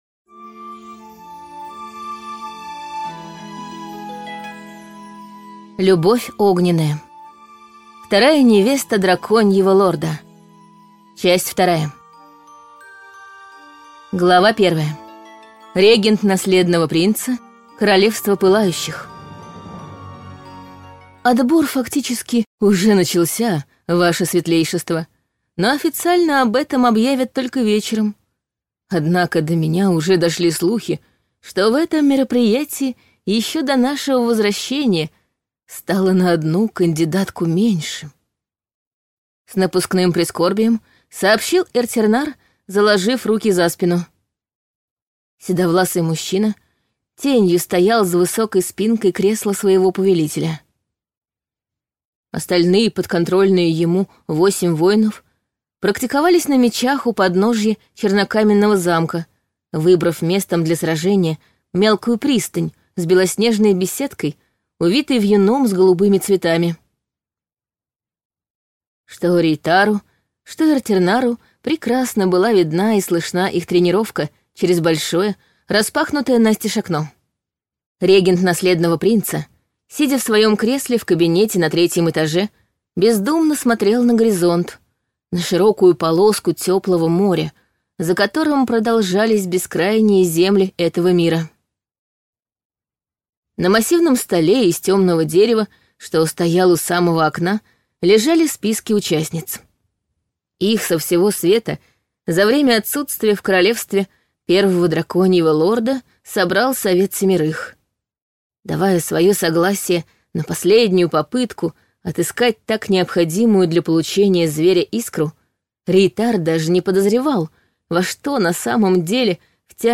Аудиокнига Вторая невеста Драконьего лорда 2 | Библиотека аудиокниг